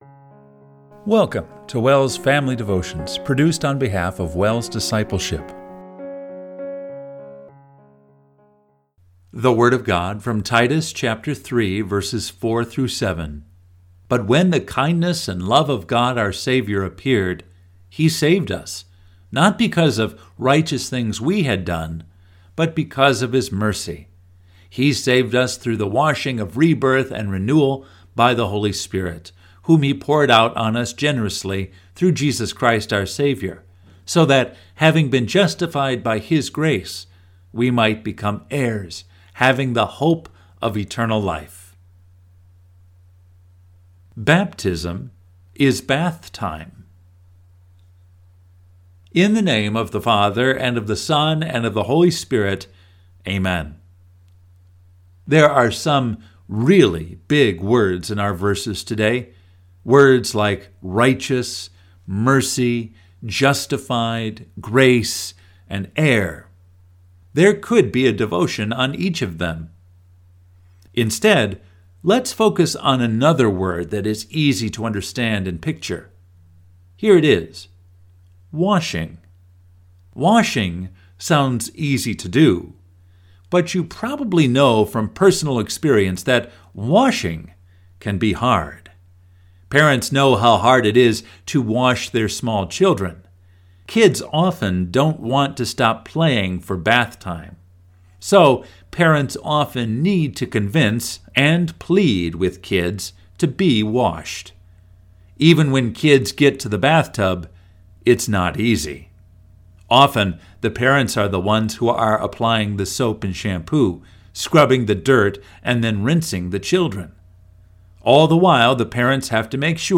Family Devotion – January 17, 2025